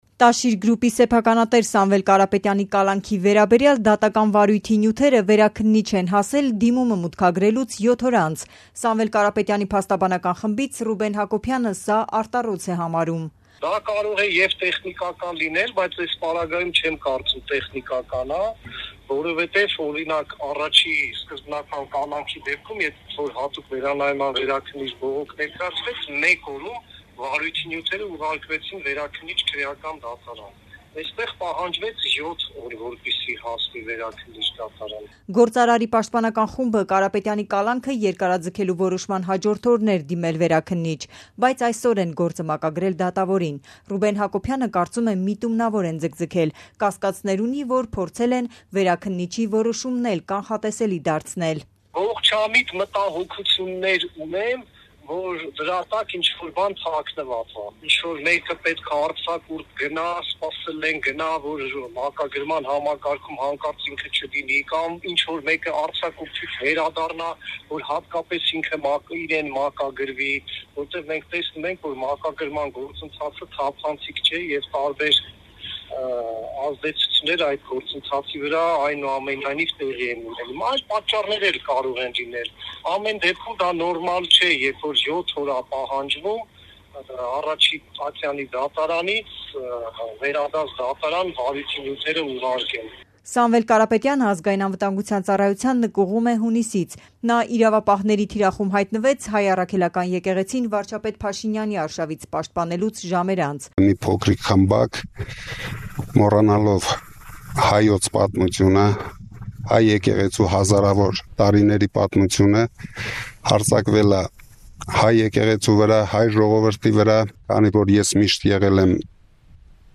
«Ազատության» ռեպորտաժը ստորև